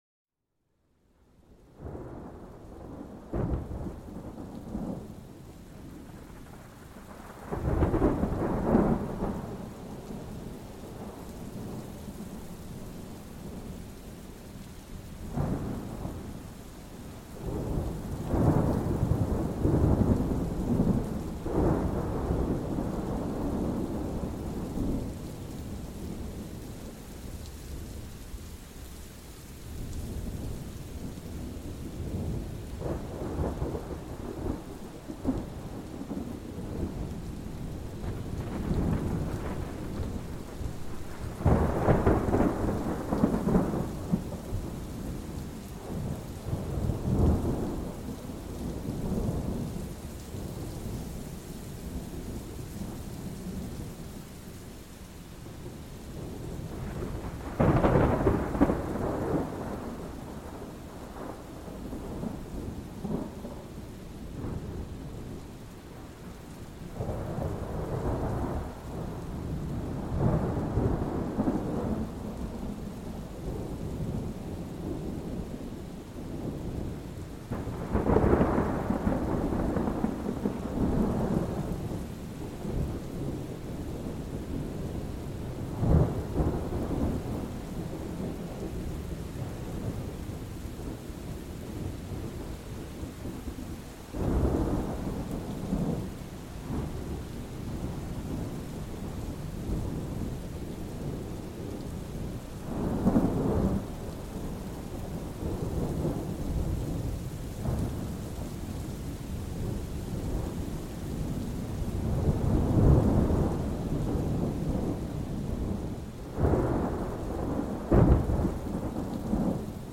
Orage et Pluie: La Force Apaisante de la Nature